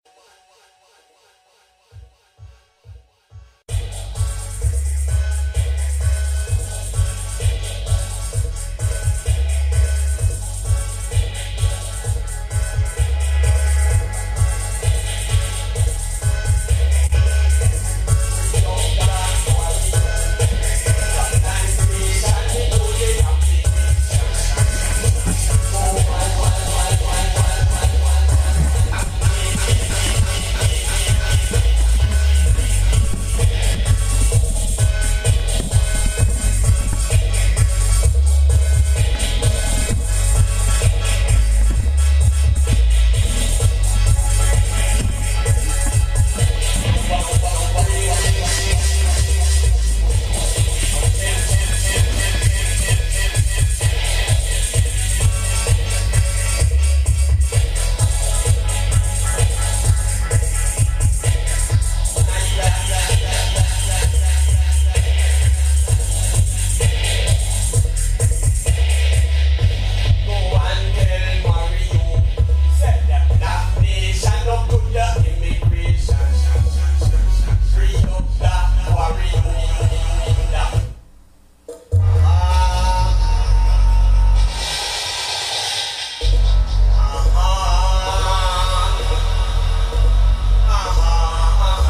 Another mighty and powerful session between 2 heavyweight sounds on east coast. Fresh dubs and specials from both sets with plenty of "New to all ears" tuns from beginning to end.
001fasimbas-sound-check-wW7GQ